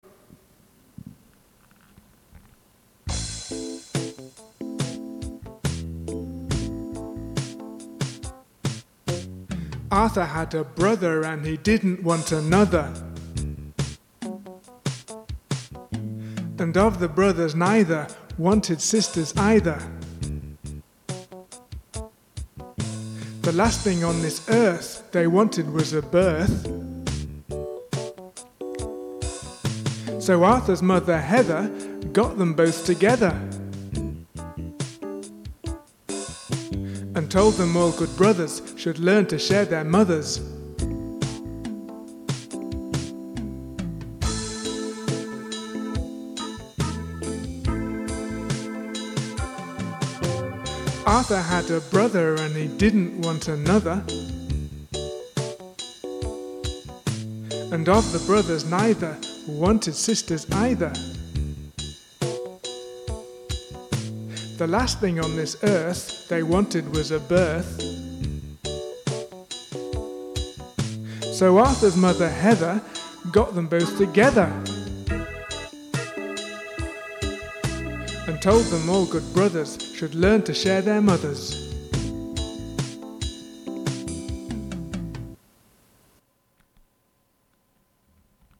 TH sounds and variations
Chanting
This chant is made up of five rhyming couplets, and it has an unusual density of TH-sounds (the hard TH in 'earth' and the soft TH in 'brother'.